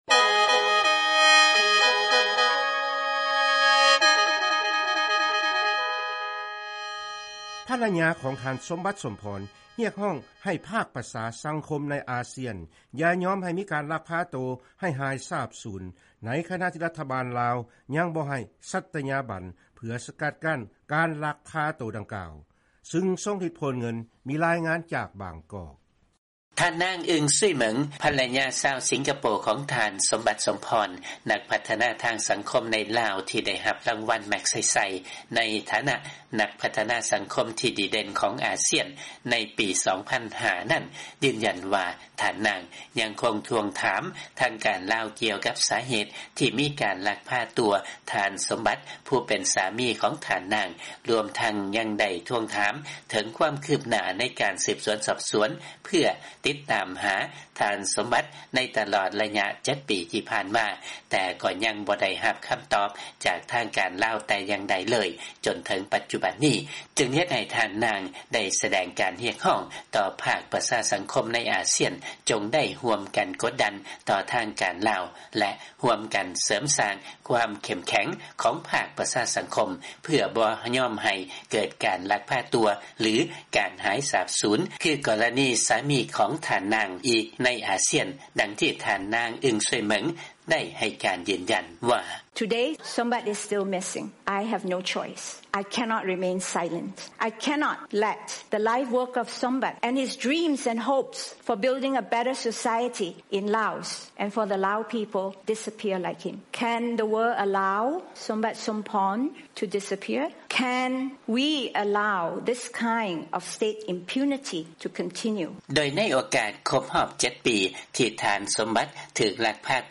ຟັງລາຍງານ ພັນລະຍາຂອງທ່ານ ສົມບັດ ສົມພອນ ຮຽກຮ້ອງໃຫ້ ອາຊຽນ ຢ່າຍອມໃຫ້ມີ ການລັກພາຕົວ ໃຫ້ຫາຍສາບສູນ